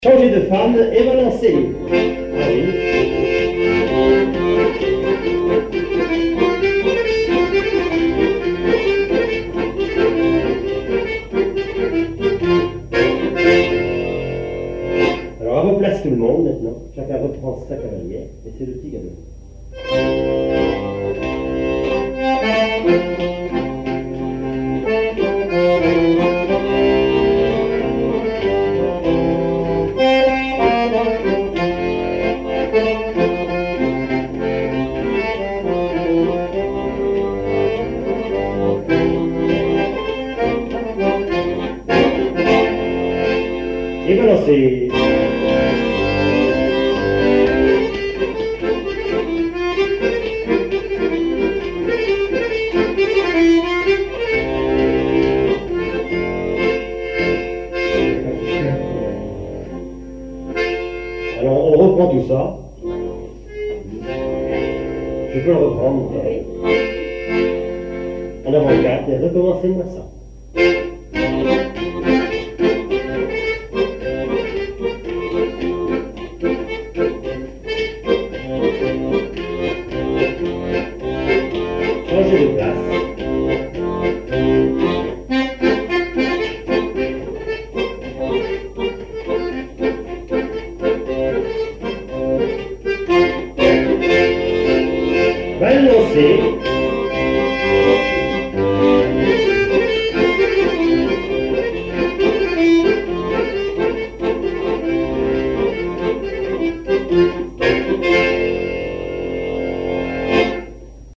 Mémoires et Patrimoines vivants - RaddO est une base de données d'archives iconographiques et sonores.
danse : quadrille : chaîne des dames
répertoire à l'accordéon diatonique
Pièce musicale inédite